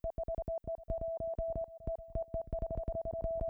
UnderwaterMorse.wav